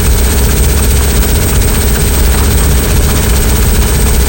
grinderloop_01.wav